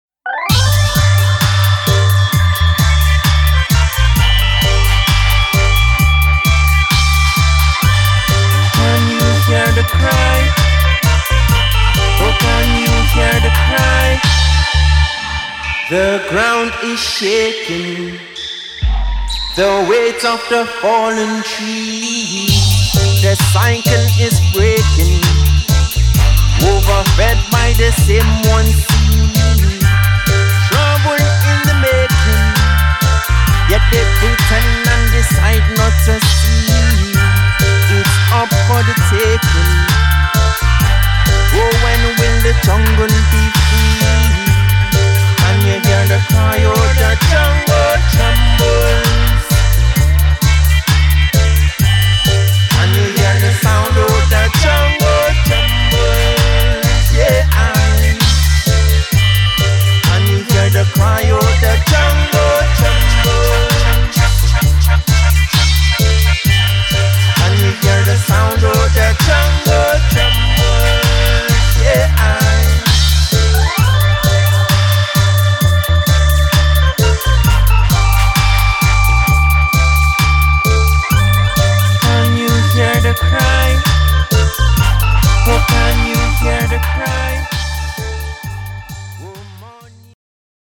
鍵盤、メロディカ、唸るようなベースライン